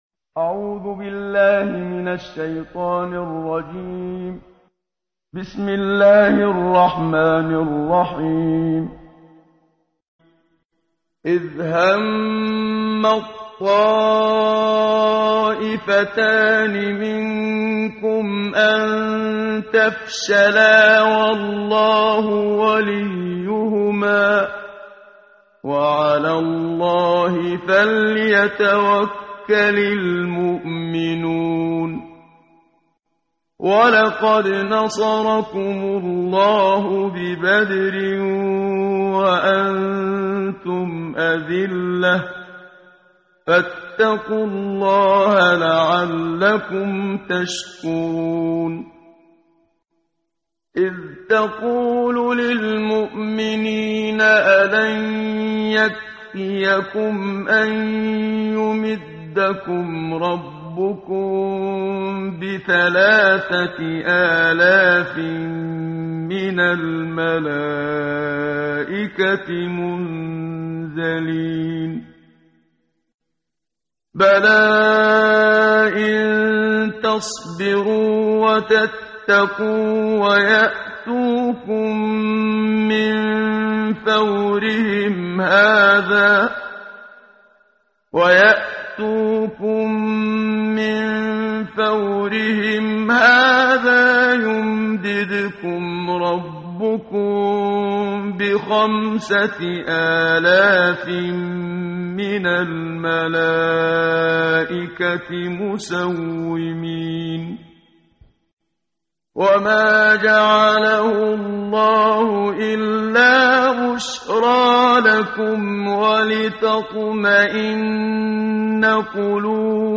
قرائت قرآن کریم ، صفحه 66، سوره مبارکه آلِ عِمرَان آیه 122 تا 132 با صدای استاد صدیق منشاوی.